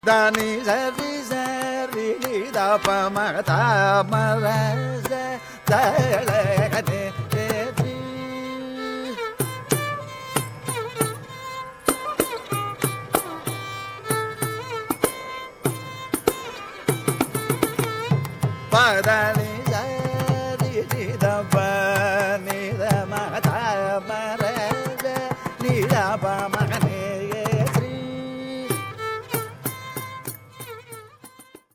") set in rāga Bhairavi and Aditāla.
violin
Recorded in December 1967 in New York.
svara kalpana 8